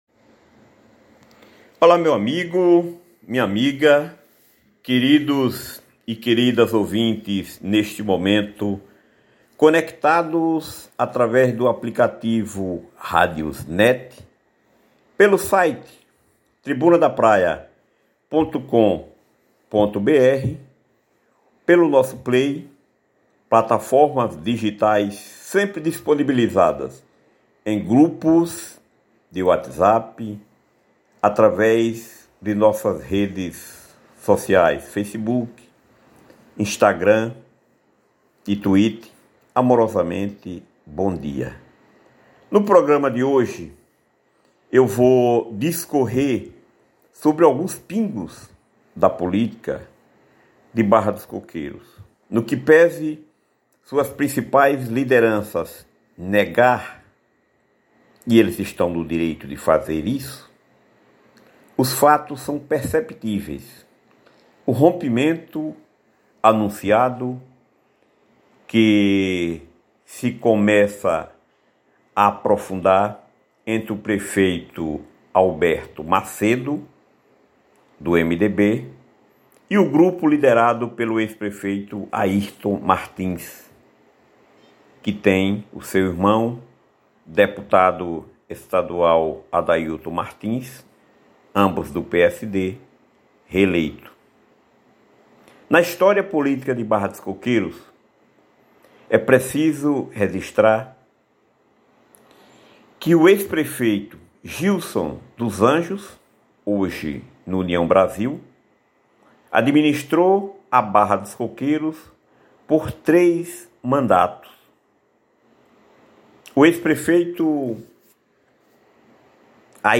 Confira a Opinião desta quinta-feira, 06, do programa A VOZ DO POVO, levado ao ar pela RÁDIO TRIBUNA DA PRAIA.